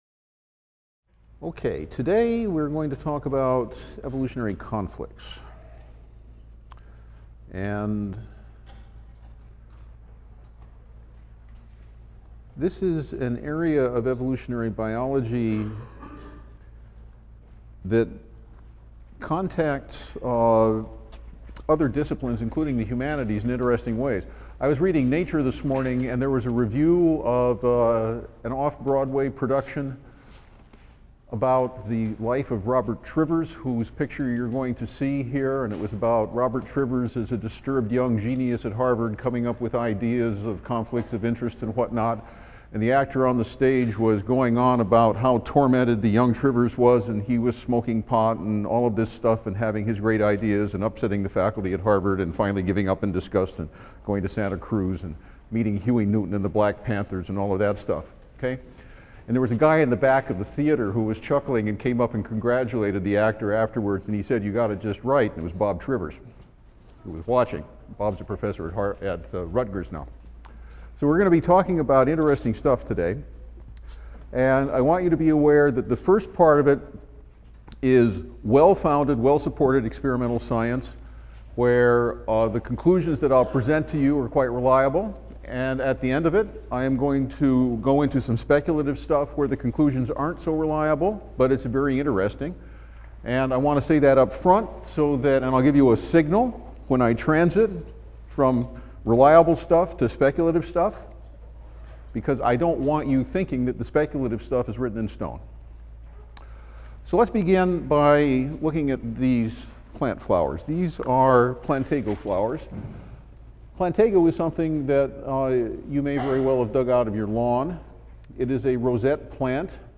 E&EB 122 - Lecture 10 - Genomic Conflict | Open Yale Courses